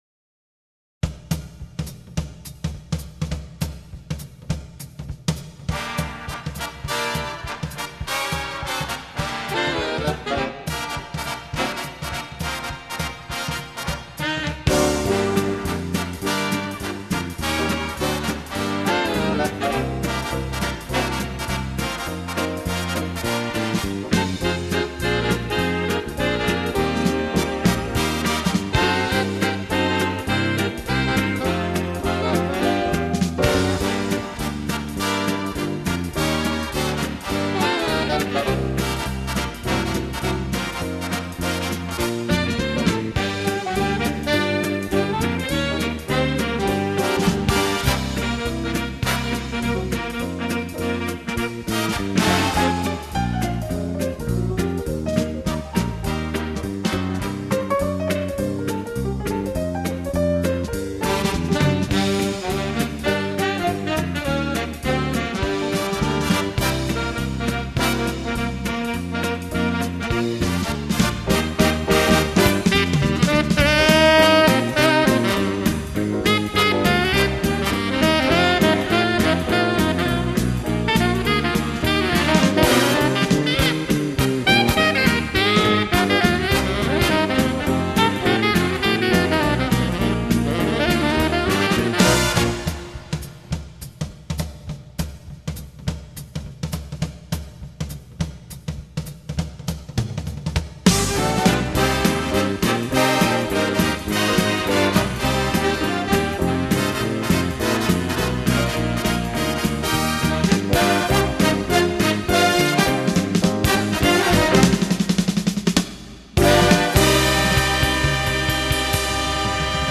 07 Quickstep